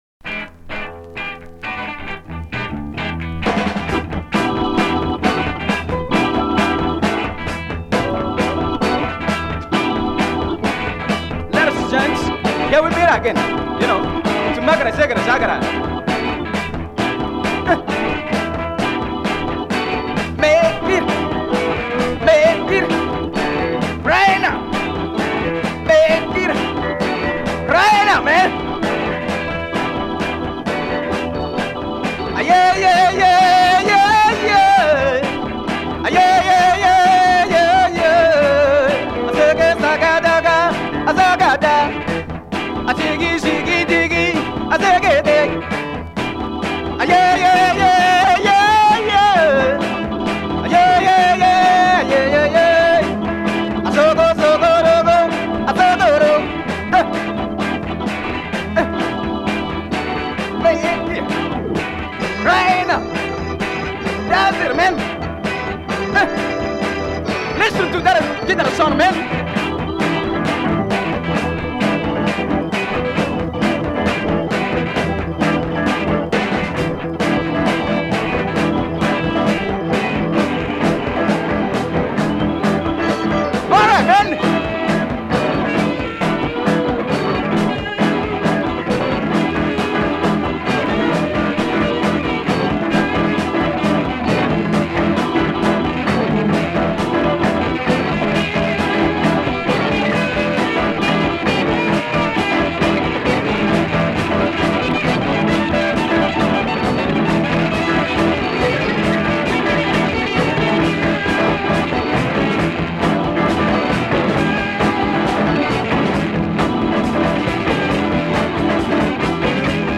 The studio production, the wild fuzz, English vocals…